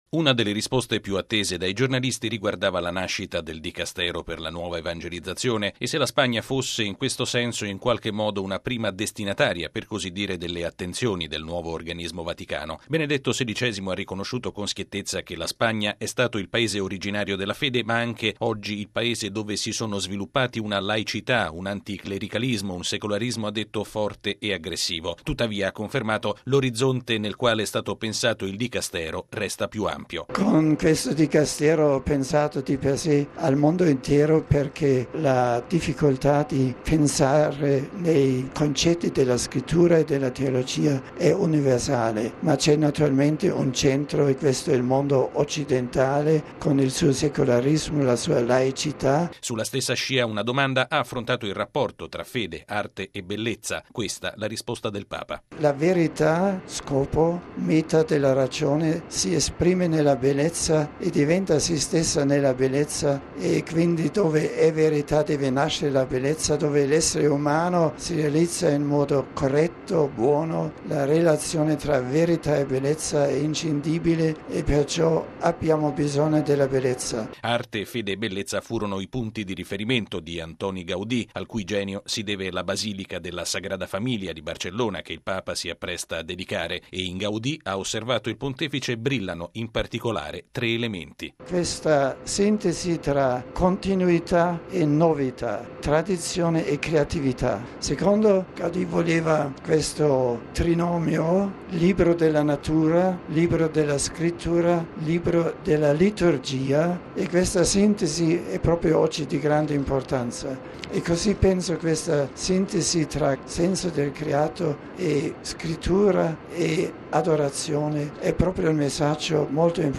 Il colloquio con i giornalisti.
◊   Un anticipo, come di consueto, dei temi portanti che caratterizzeranno questa visita apostolica in Spagna Benedetto XVI lo aveva offerto sull’aereo papale, intrattenendosi durante il volo con i giornalisti presenti a bordo. In particolare, il Papa ha auspicato l’incontro e non lo scontro tra fede e laicità, una questione - ha spiegato - nella quale la cultura spagnola riveste un ruolo centrale.